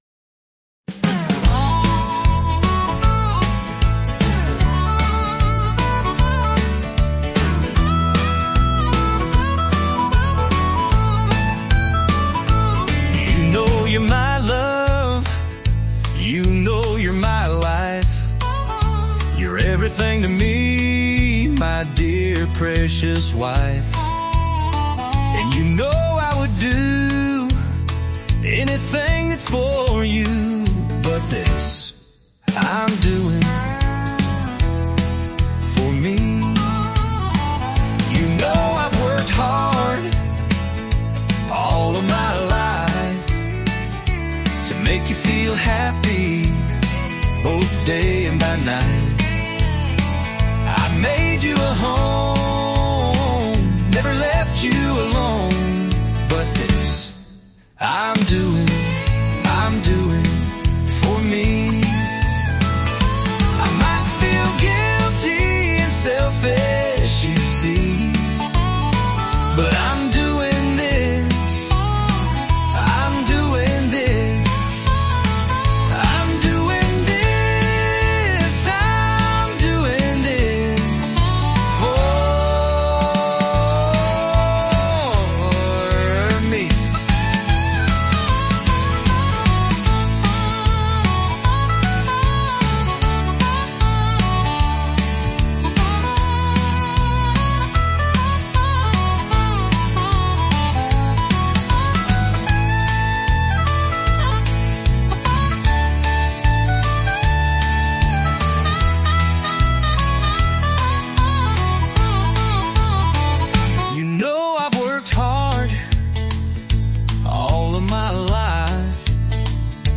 I have written another Country song for all my soupper friends who maybe feel a little bit selfish at times ...